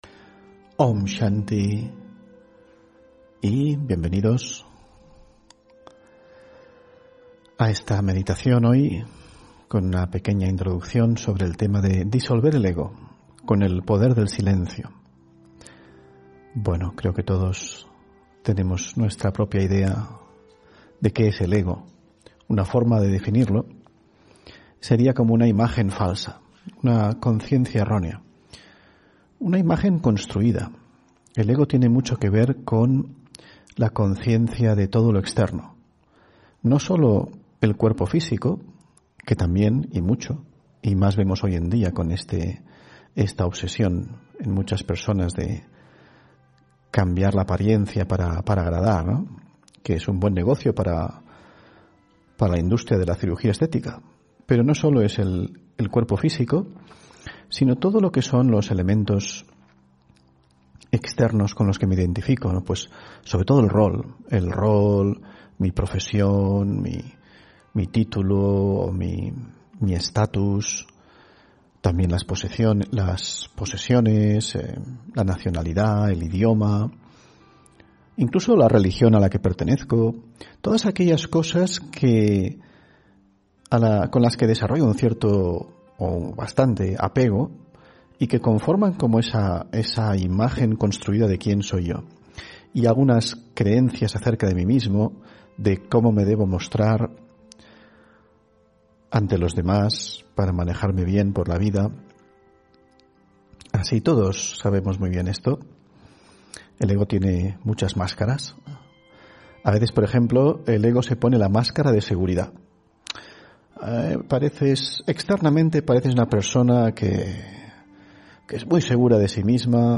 Meditación y conferencia: Disolver el ego con el poder del silencio (16 Noviembre 2021)